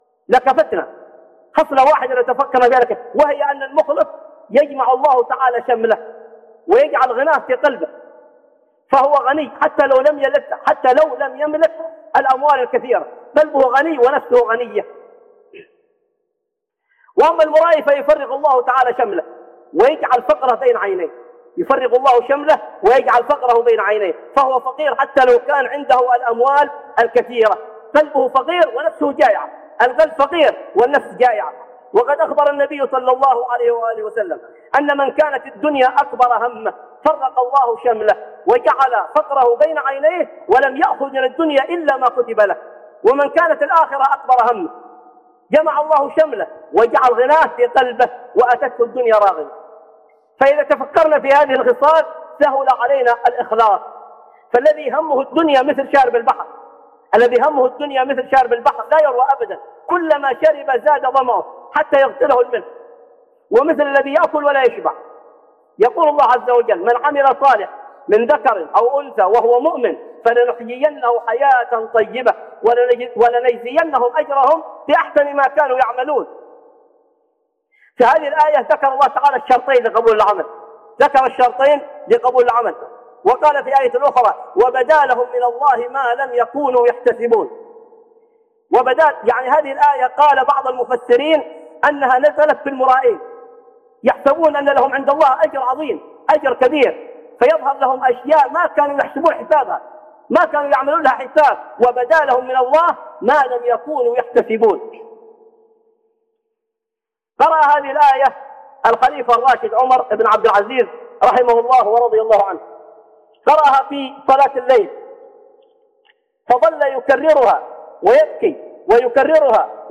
التحذير من الرياء وإرادة غير الله بالعمل - خطب